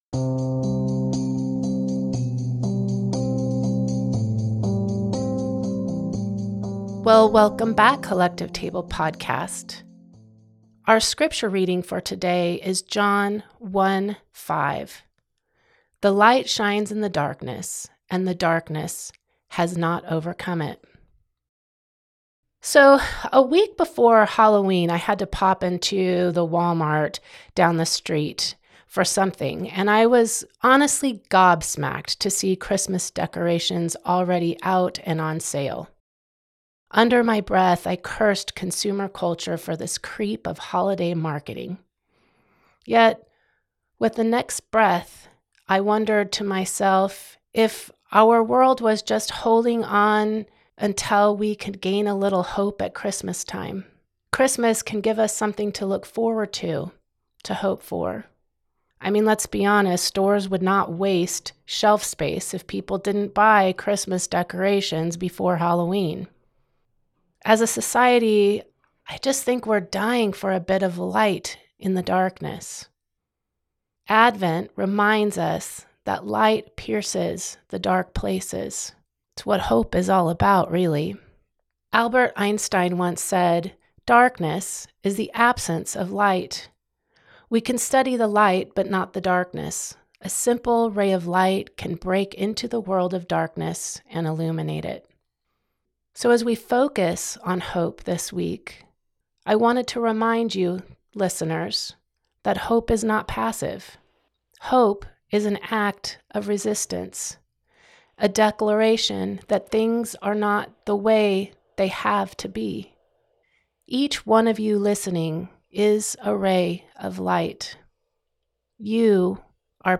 special reflection